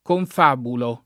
confabulo [ konf # bulo ]